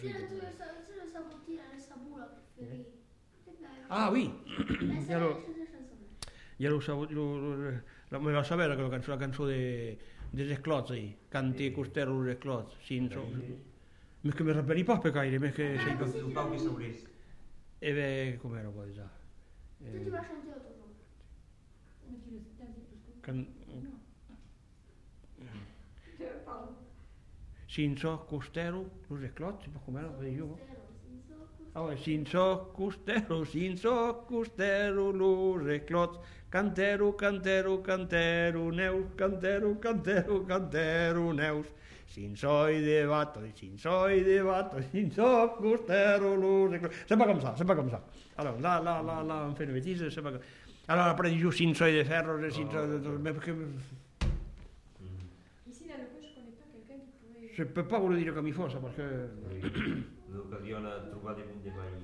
Genre : chant
Effectif : 1
Type de voix : voix d'homme
Production du son : chanté
Danse : valse